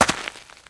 STEPS Gravel, Walk 01.wav